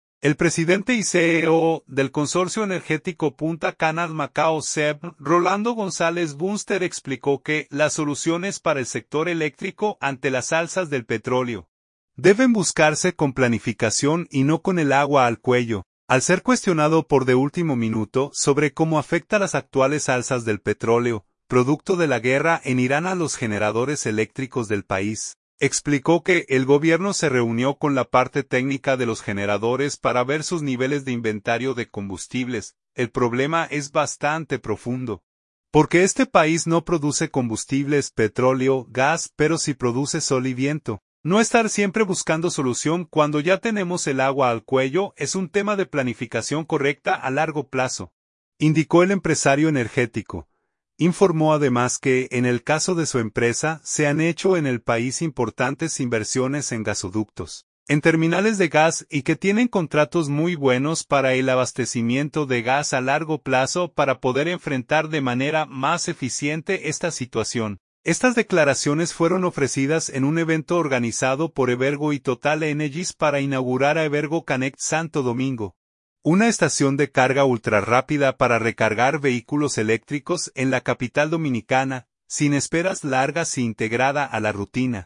Estas declaraciones fueron ofrecidas en un evento organizado por Evergo y Total Energies para inaugurar a Evergo Connect Santo Domingo, una estación de carga ultrarrápida para recargar vehículos eléctricos en la capital dominicana, sin esperas largas e integrada a la rutina.